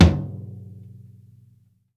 DEADFLOOR -R.wav